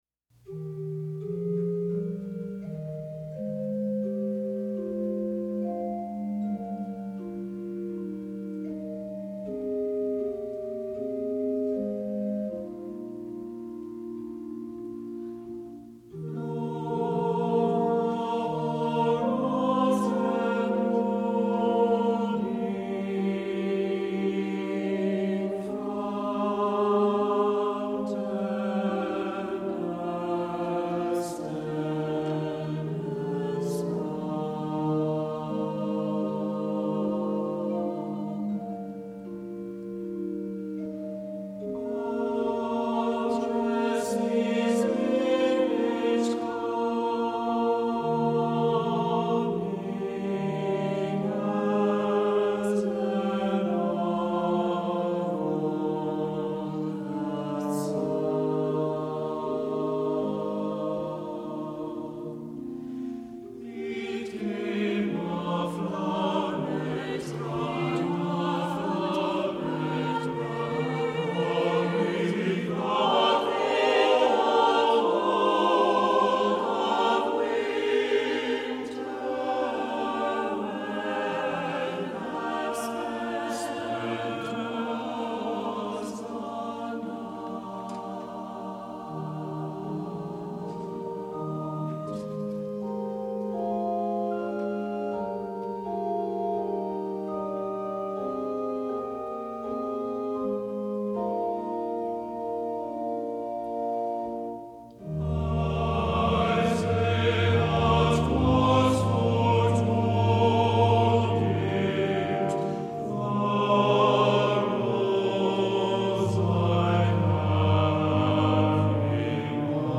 Voicing: SATB and Organ